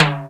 • Modern Clean Tom Drum One Shot D Key 27.wav
Royality free tom tuned to the D note. Loudest frequency: 1771Hz
modern-clean-tom-drum-one-shot-d-key-27-odK.wav